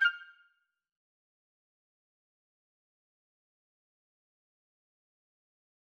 obsydianx-interface-sfx-pack-1
back_style_4_002.wav